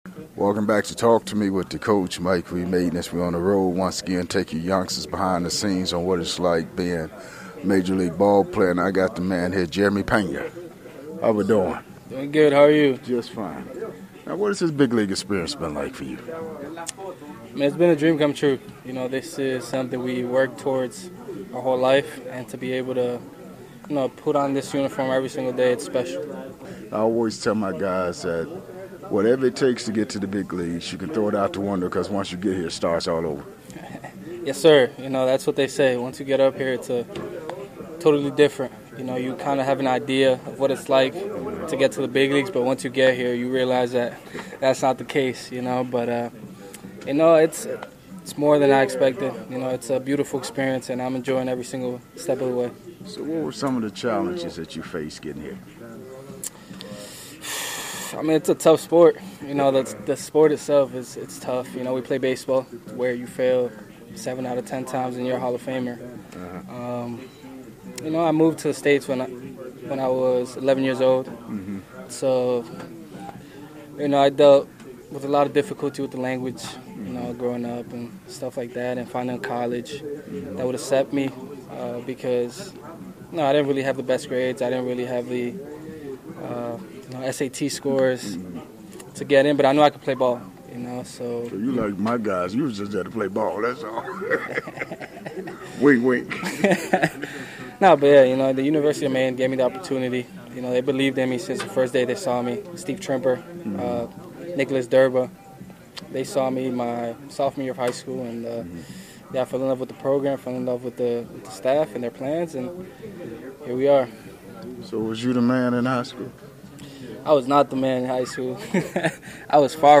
One on one with the MLB stars of the game.